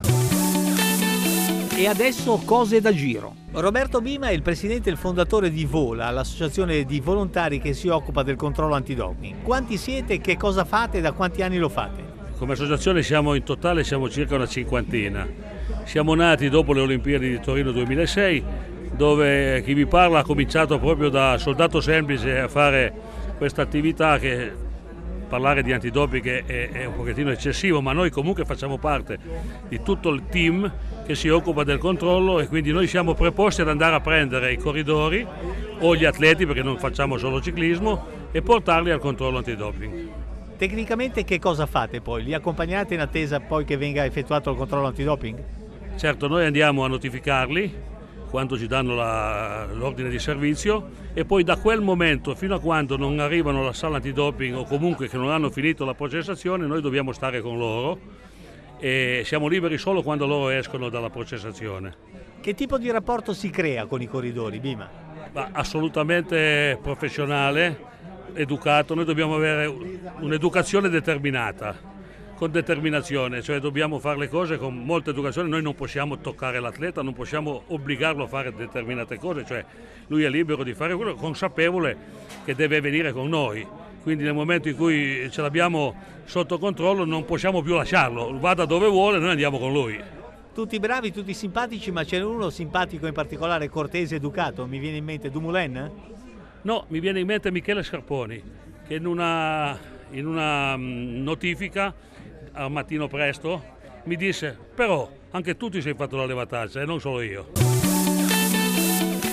10 maggio 2020, Intervista